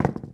drop1.wav